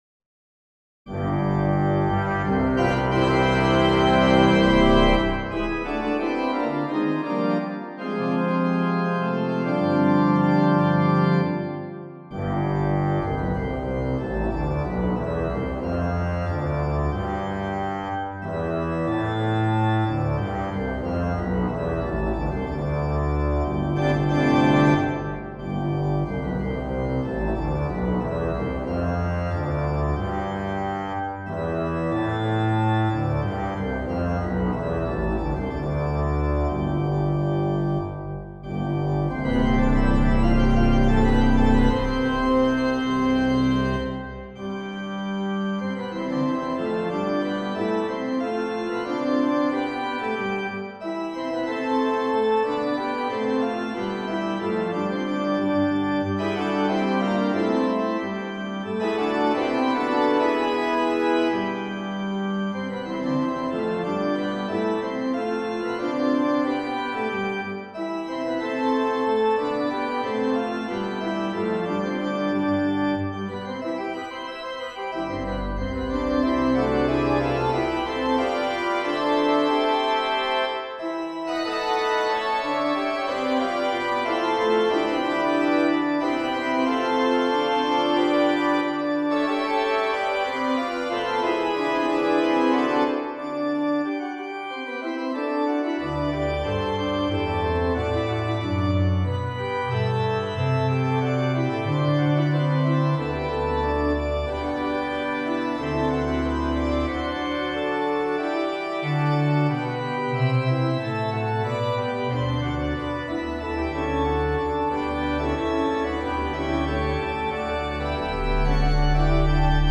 for organ
A set of rising fourths forms the general rationale for the subject, as seen in the opening solo pedal line. After a gesture of harmonically distant chords, the subject sings out its unabashed major tonic domain, Thereafter entrances come in the traditional dominant and tonic regions, with varying counterpoint, making a simple structure. The registration imagined is massed choirs, loud and louder, throughout.
6 pages, circa 5' 15" an MP3 demo is here: